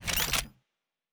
Weapon 05 Reload 1 (Laser).wav